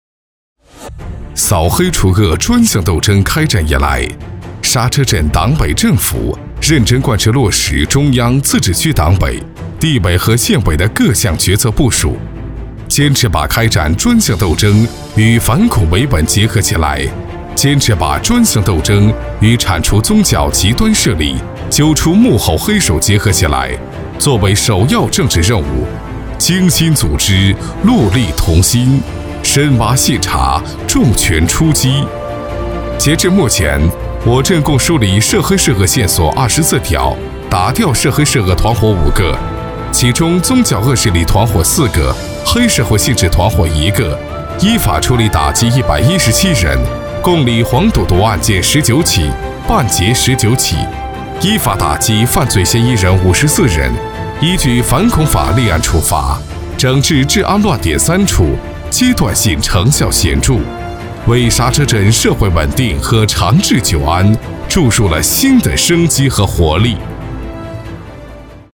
男国语217